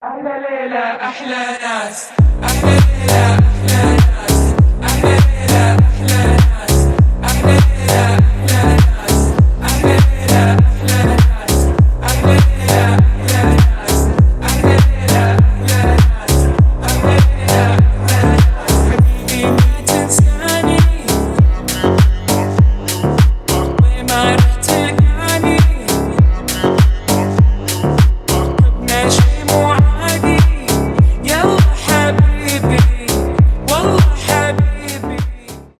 клубные , арабские , deep house